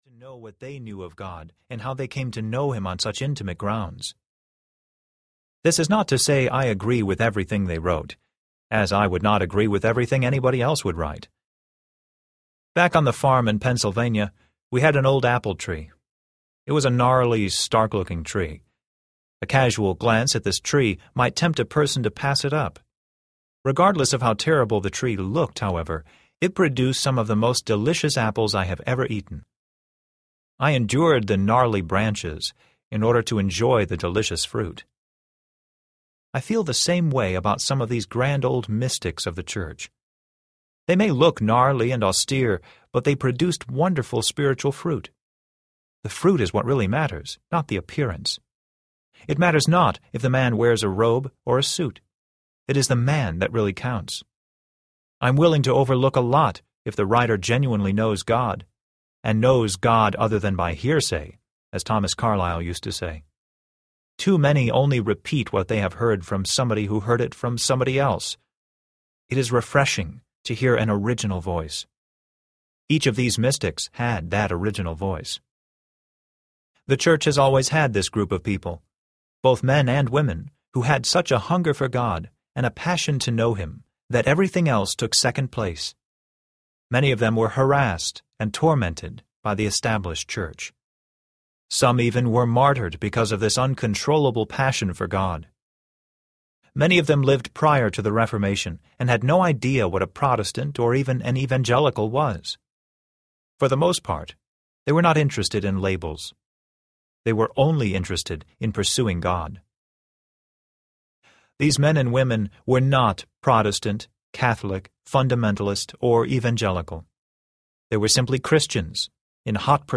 The Crucified Life Audiobook
6.75 Hrs. – Unabridged